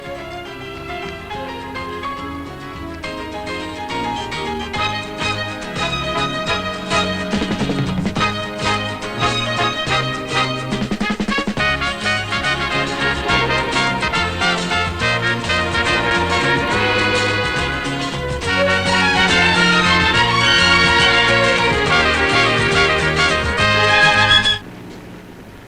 им озвучена короткая сцена на танцполе в ресторане.